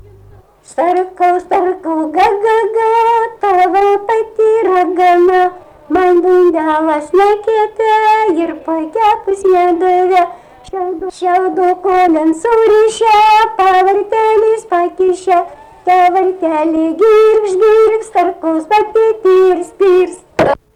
daina, vaikų
Bagdoniškis
vokalinis